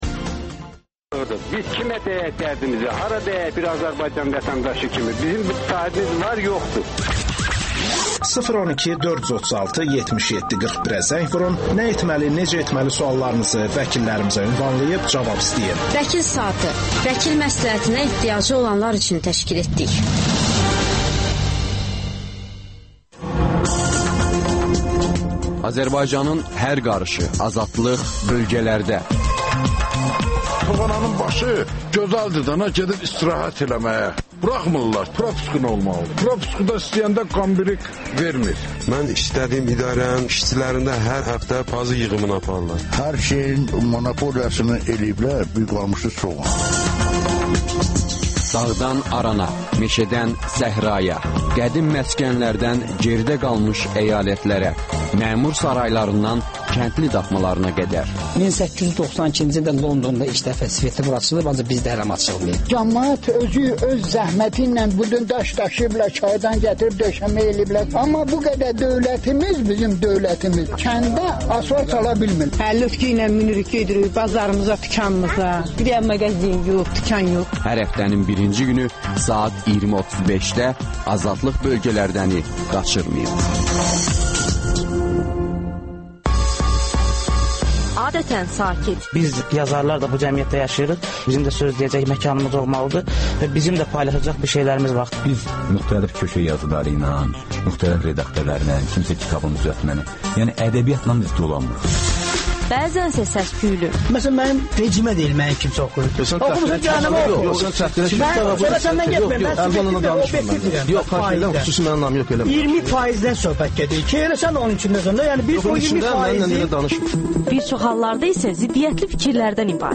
debatı